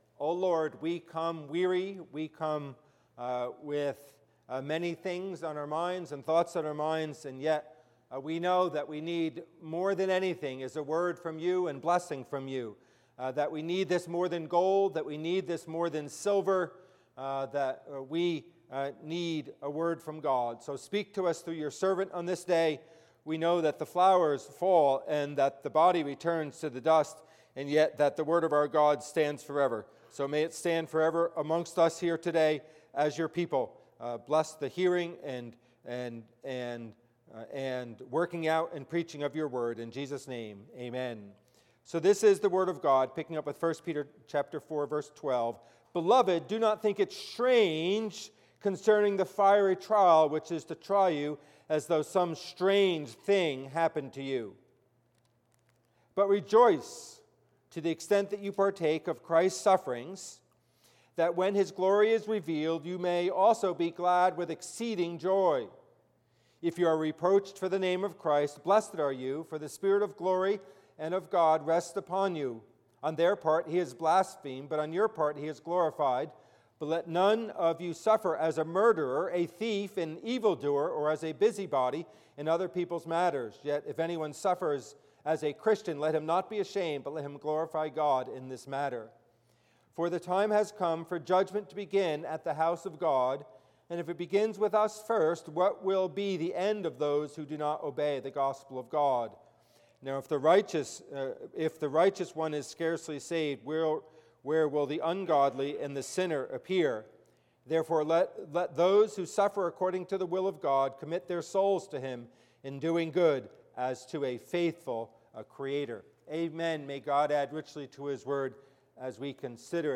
Passage: 1 Peter 4:12-19 Service Type: Worship Service